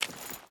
Water Chain Walk 4.ogg